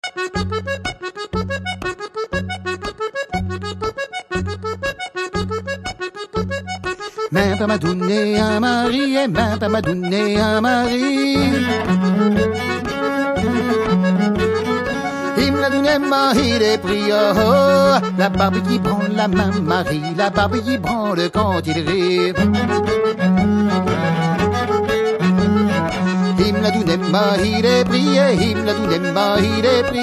La Ronde avant-deux est une danse en ronde du Poitou.